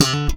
ALEM FUNK D5.wav